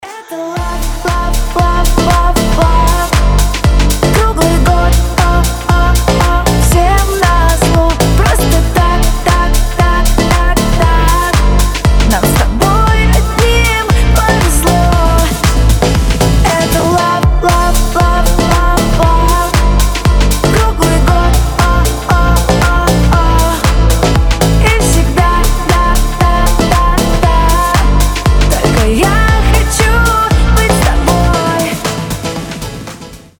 • Качество: 320, Stereo
женский голос
Club House